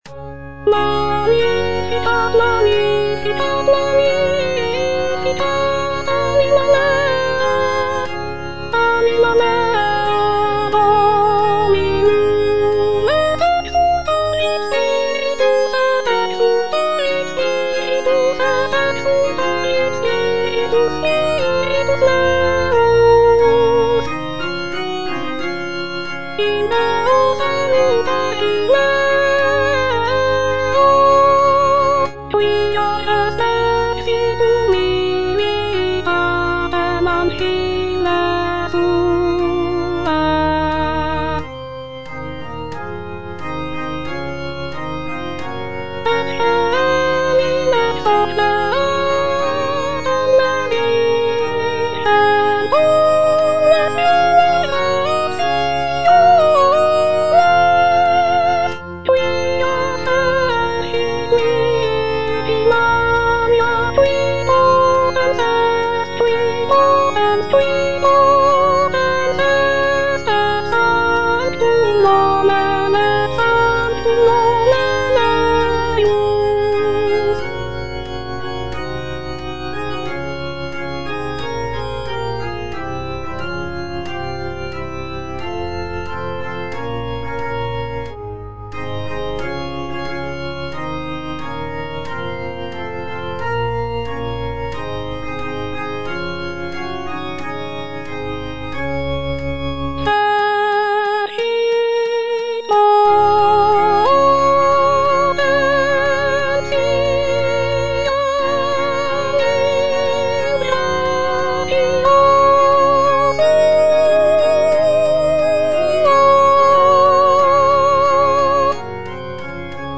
J. PACHELBEL - MAGNIFICAT IN D (EDITION 2) (A = 415 Hz) Soprano (Voice with metronome) Ads stop: